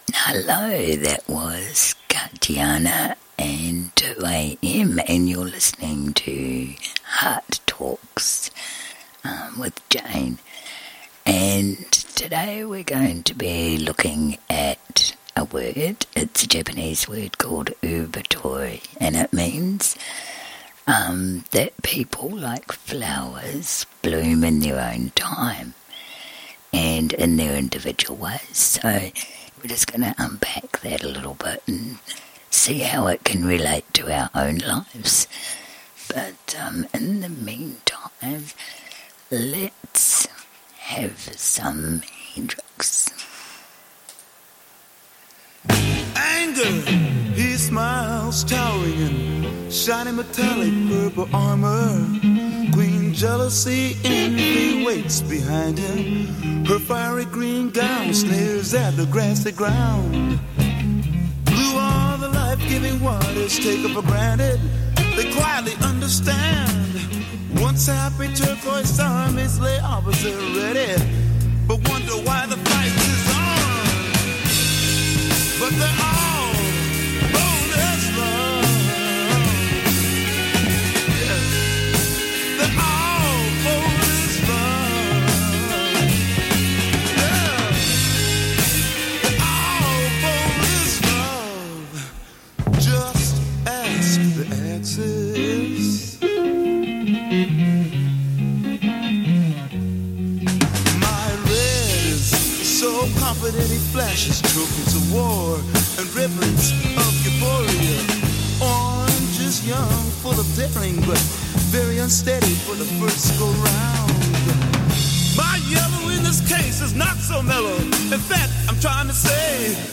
With a bit of music in between. Country/Rock